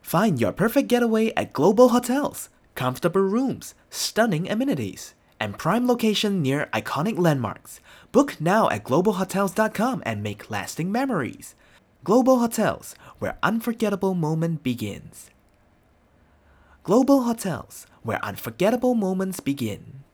Male
Energetic Young Low Conversational
Global Hotels Conversational